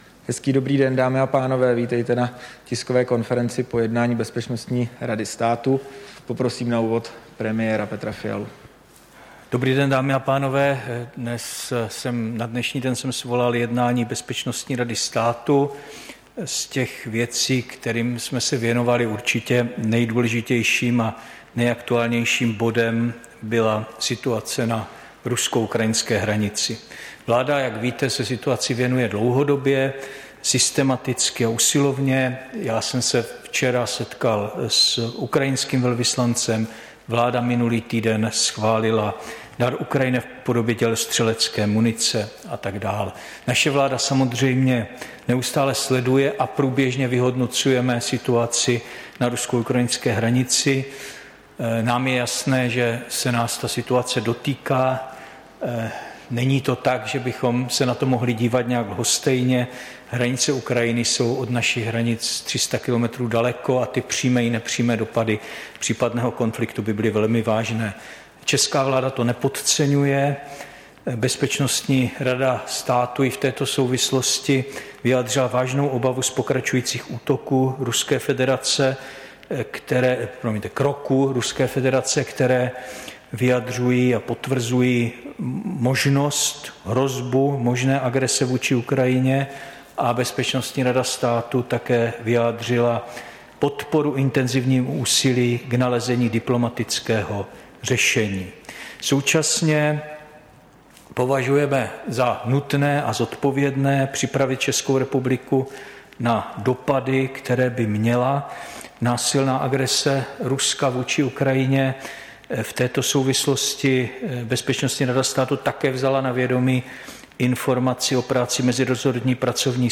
Tisková konference po jednání Bezpečnostní rady státu, 1. února 2022